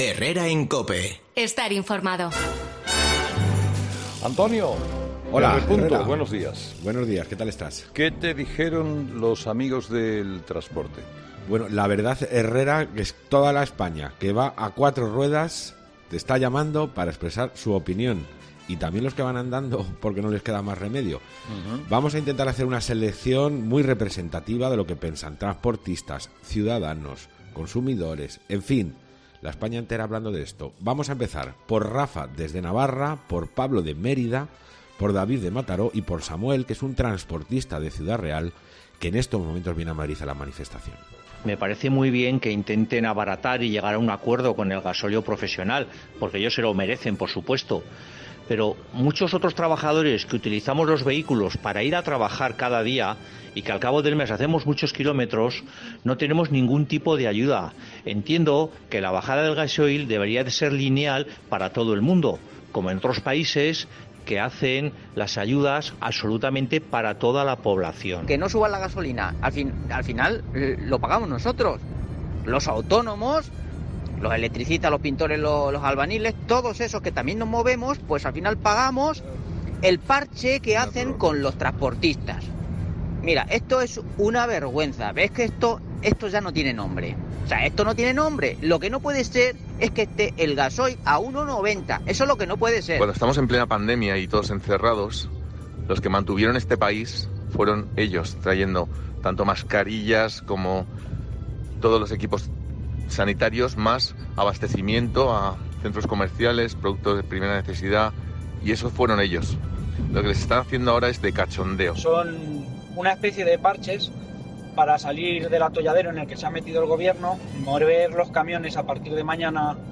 La tertulia de los oyentes